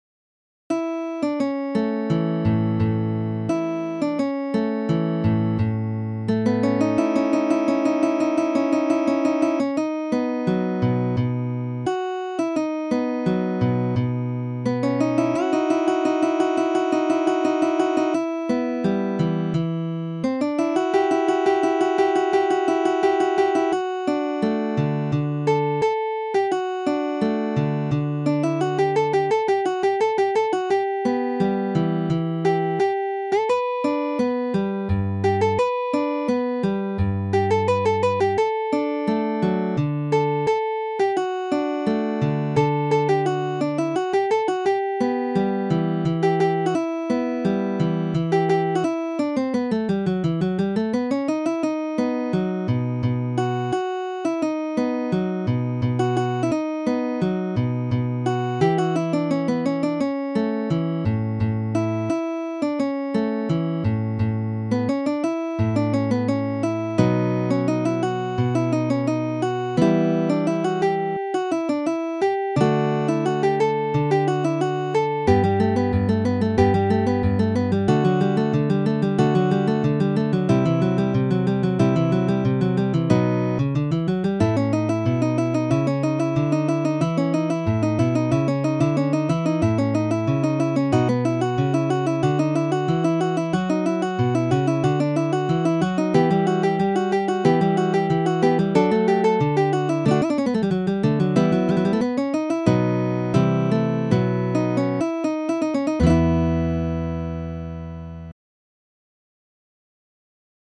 Midi音楽が聴けます 3 220円